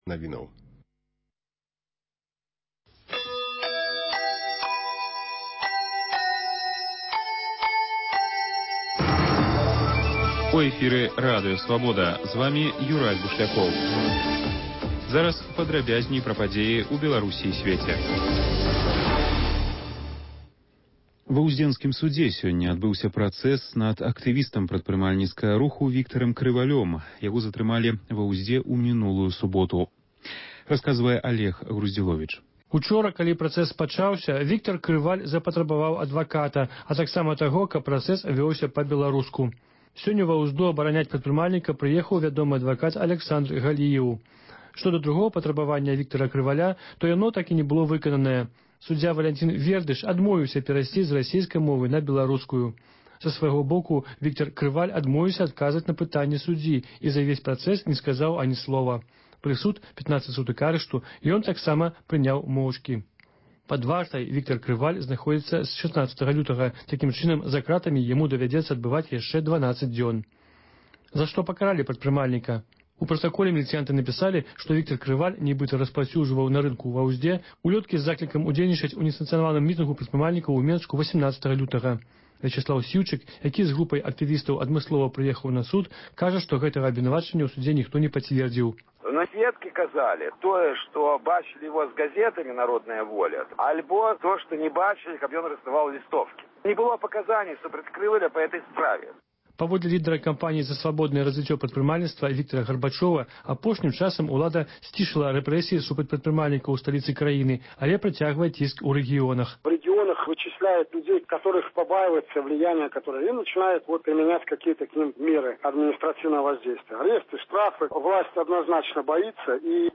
Рэпартажы і паведамленьні нашых карэспандэнтаў, званкі слухачоў, апытаньні на вуліцах беларускіх гарадоў і мястэчак.